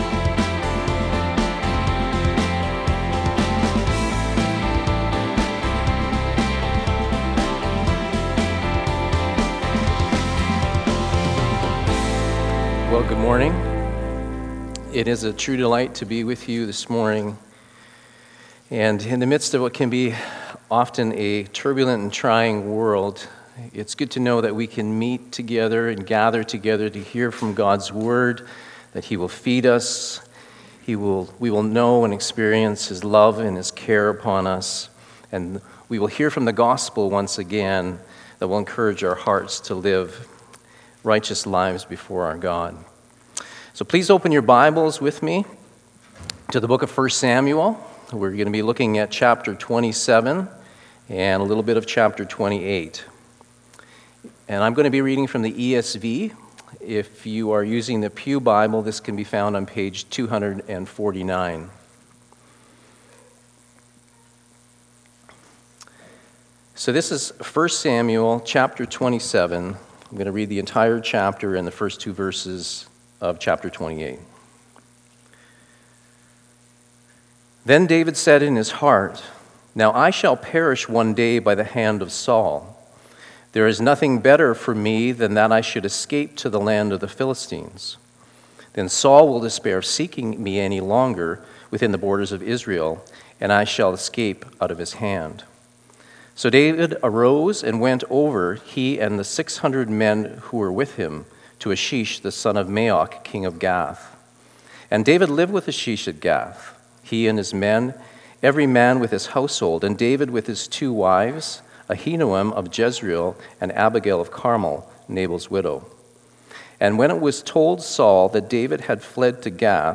Guest Preacher // Jan 12 // Righteous Living in Troubling Times